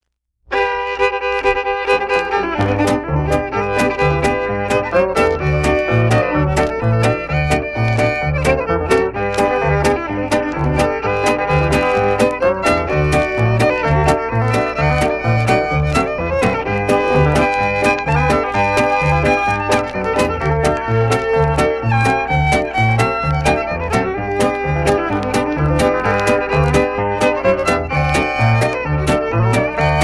Patter (two instrumentals)